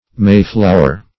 Mayflower \May"flow`er\, n. (Bot.)